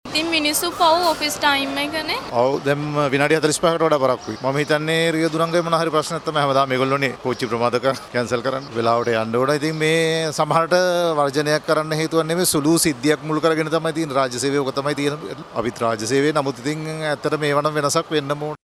මේ එම අවස්ථාවේදී දුම්රිය මගීන් මාධ්‍යට දක්වා ඇති අදහස්.